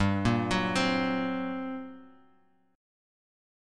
GmM11 Chord
Listen to GmM11 strummed